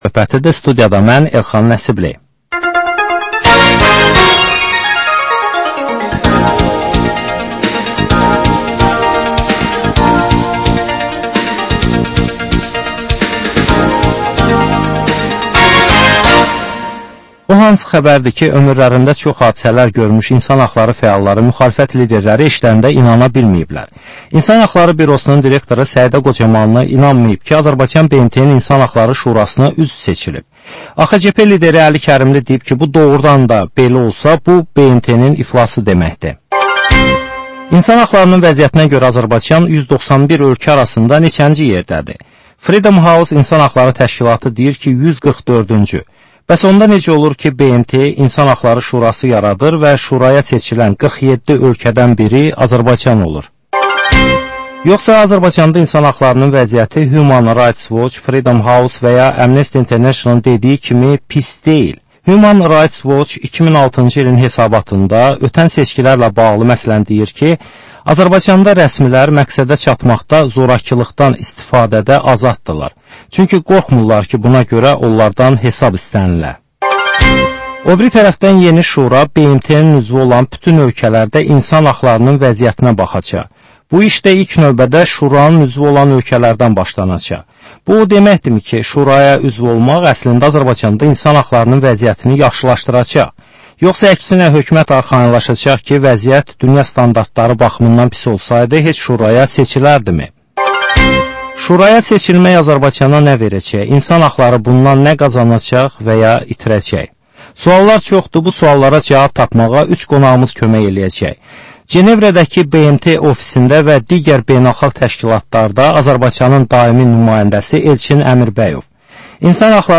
Dəyirmi masa söhbətinin təkrarı.